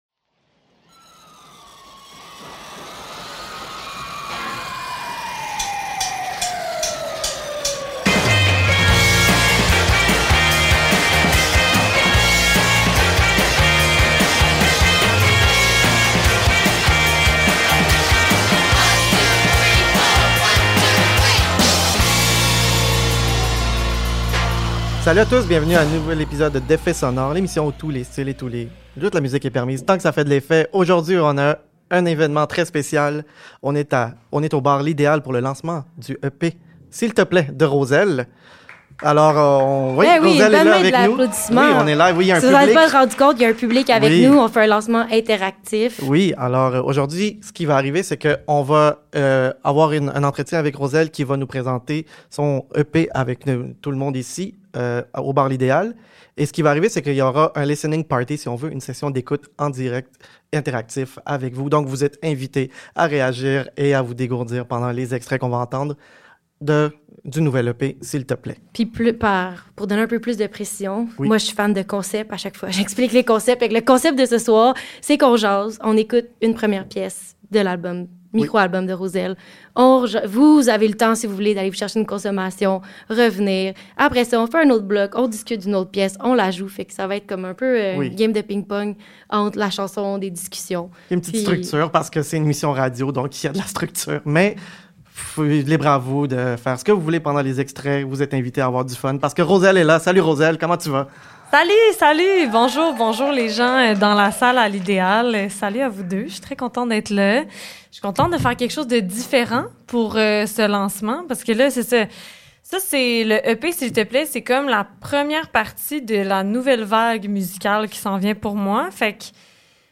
L’épisode a été enregistré le 20 mars au bar L’Idéal au cœur d’une session d’écoute interactive devant public.